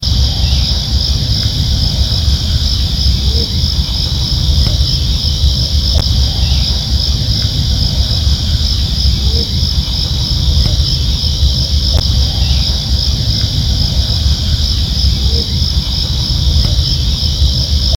Lechuzón Orejudo (Asio clamator)
Nombre en inglés: Striped Owl
Localidad o área protegida: Concordia
Condición: Silvestre
Certeza: Observada, Vocalización Grabada
Lechuzon-orejudo.mp3